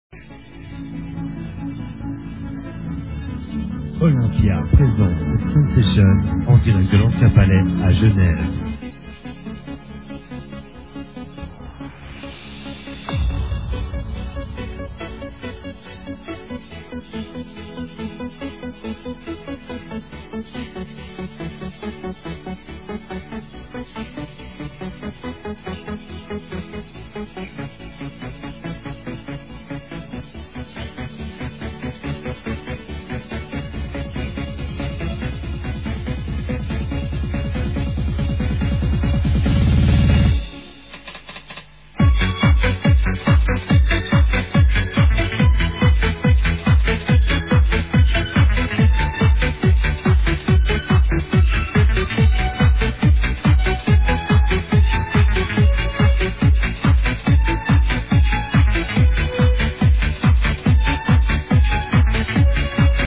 mixed with some trance and a french vocal sample